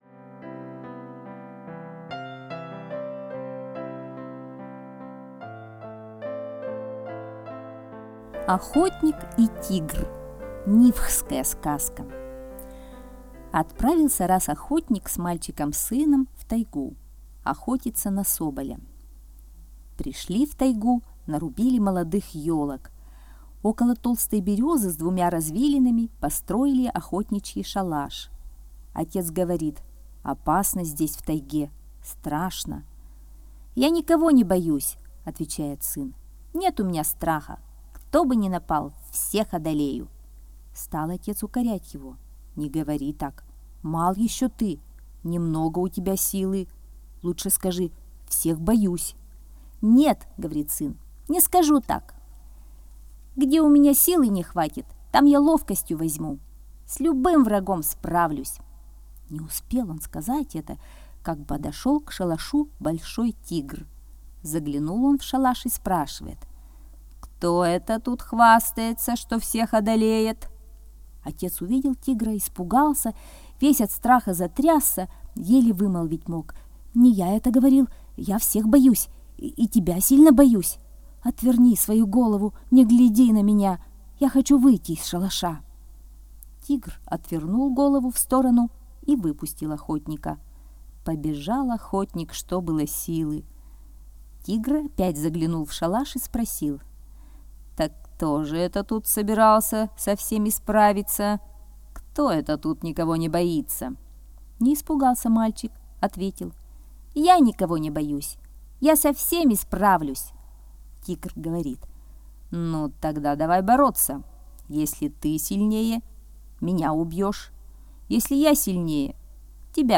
Охотник и тигр - нивхская аудиосказка - слушать онлайн